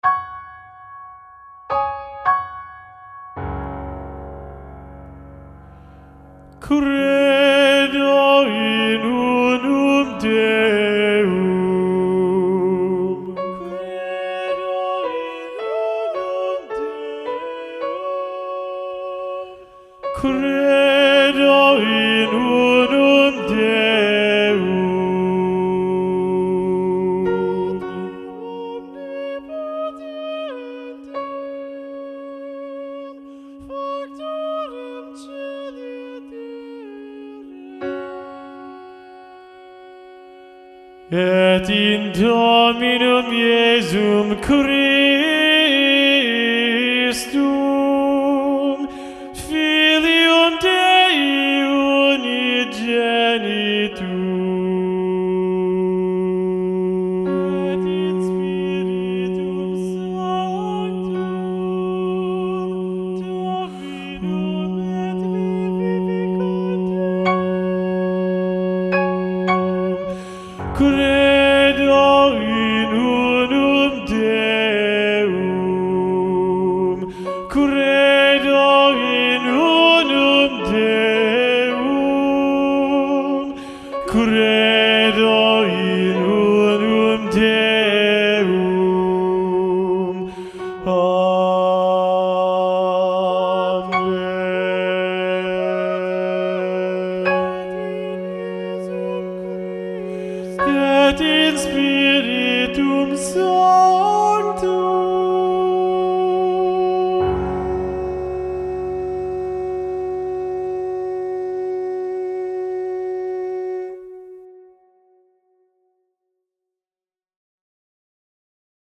Lo ULTIMO Tenores
Credo-Missa-Festiva-SATB-Tenor-Predominant-John-Leavitt.mp3